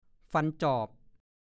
ฐานข้อมูลพจนานุกรมภาษาโคราช